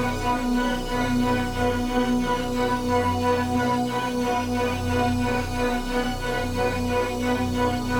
Index of /musicradar/dystopian-drone-samples/Tempo Loops/90bpm
DD_TempoDroneD_90-B.wav